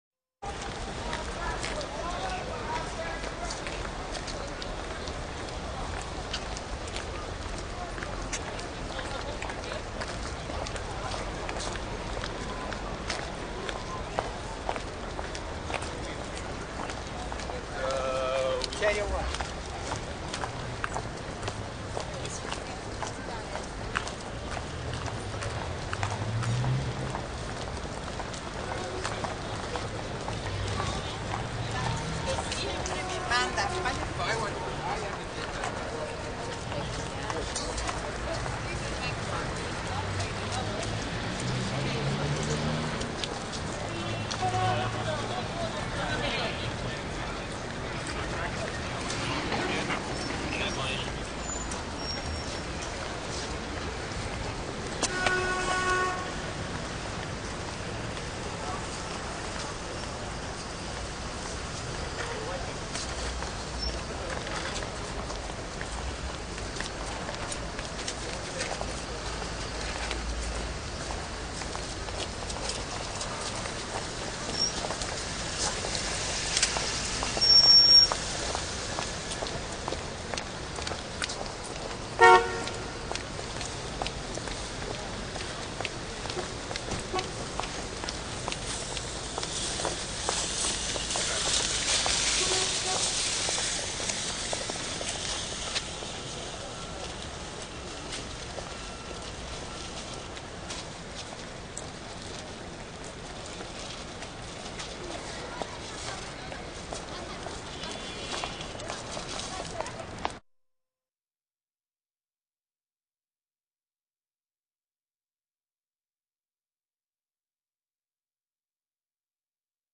Звук города с пешеходами
Пешеходы на тротуаре 2мин 6 сек
Zvuk_goroda_peshehody.mp3